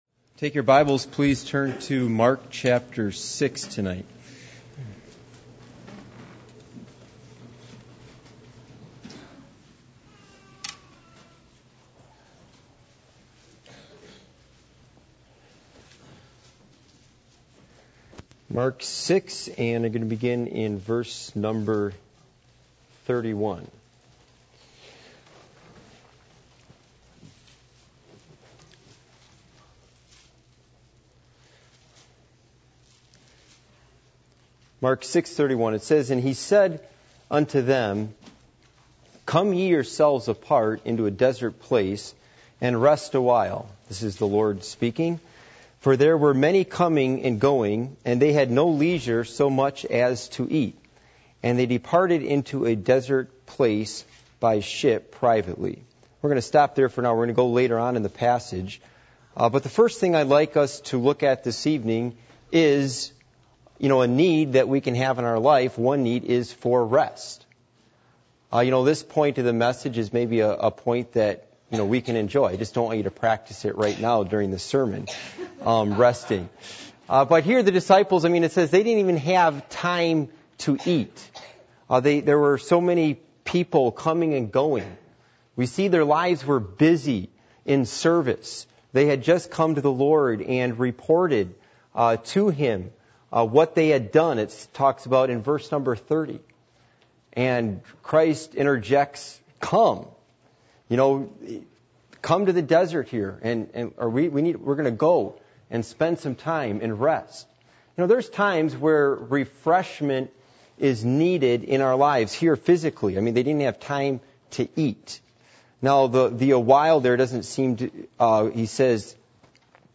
Passage: Mark 6:31-34, Mark 6:46-52, Matthew 9:36 Service Type: Midweek Meeting